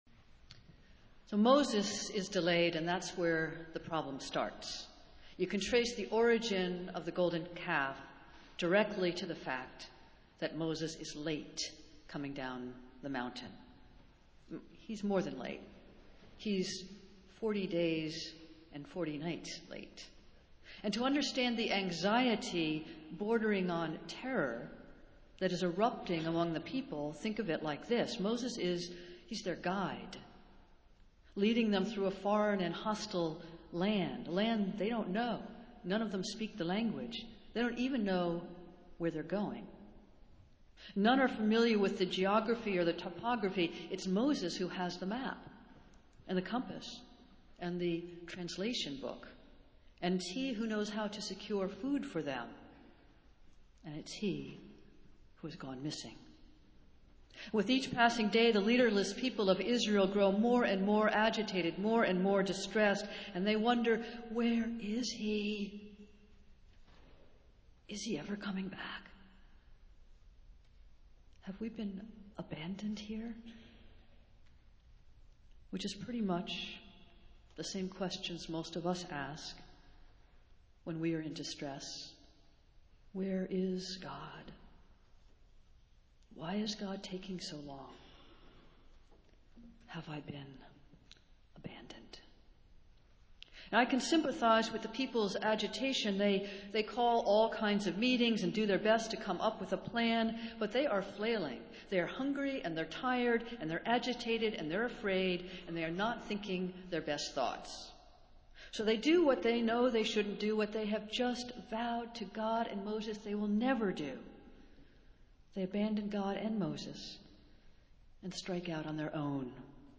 Festival Worship - Eighteenth Sunday after Pentecost